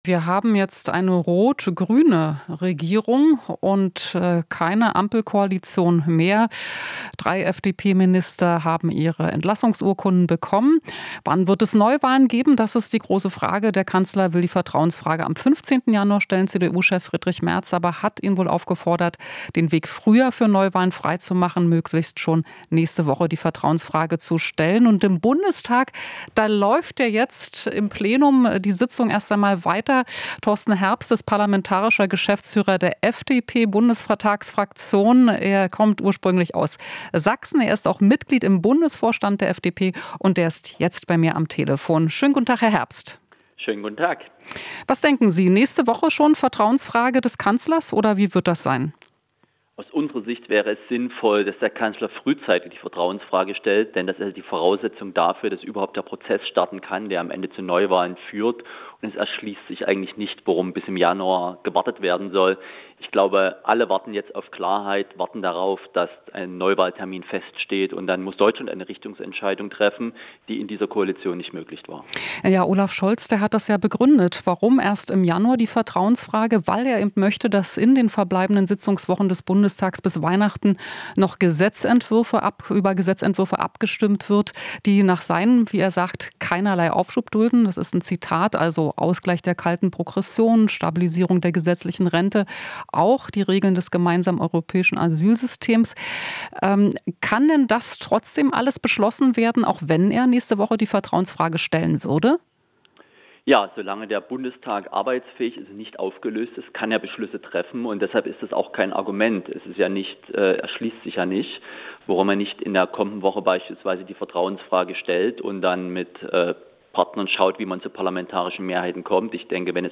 Interview - Herbst (FDP): "Alle warten jetzt auf Klarheit"